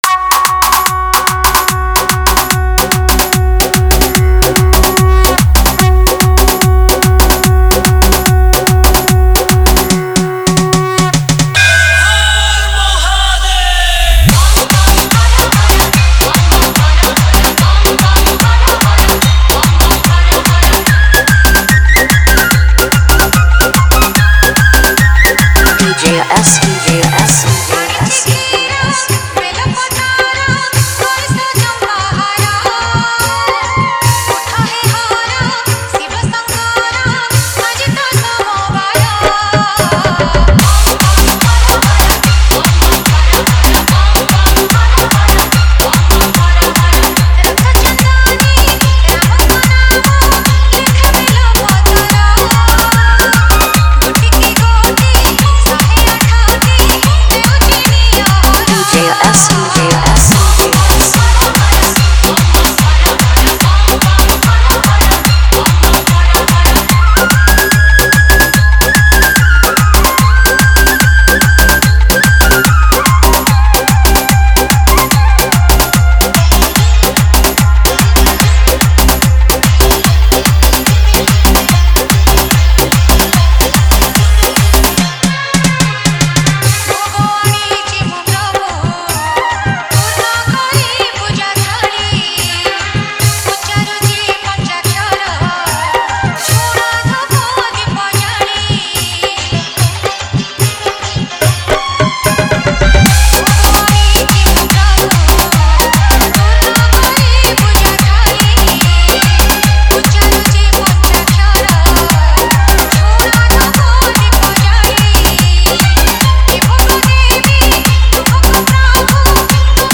Shivratri Special DJ Remix Songs